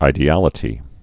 (īdē-ălĭ-tē)